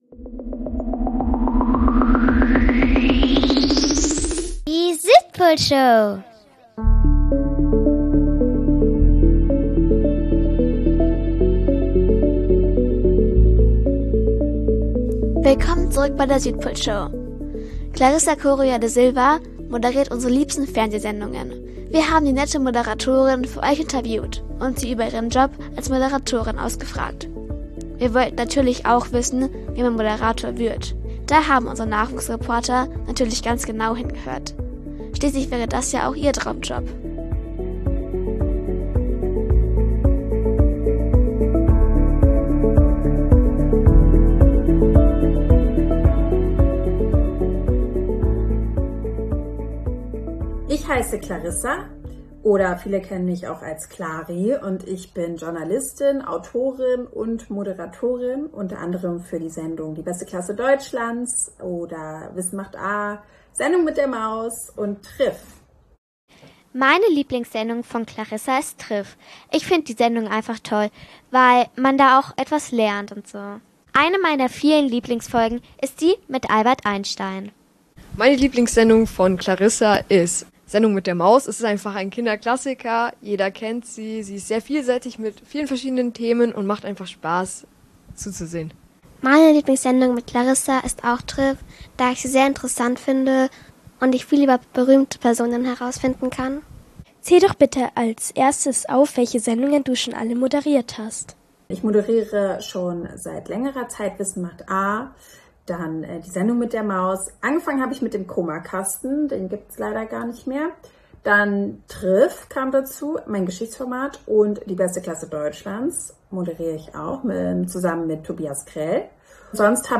Interview mit Clarissa Corrêa da Silva│Moderatorin ~ Südpolshow | Radio Feierwerk 92,4 Podcast